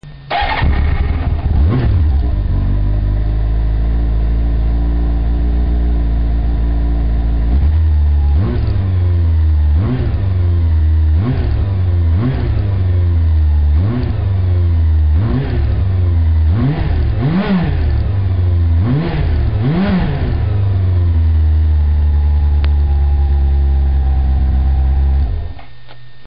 Mein neuer Arrow Endtopf.
Und er klingt auch wesentlich besser. Damit wird auch eine ZR-7 (zumindest akustisch) pfeilschnell.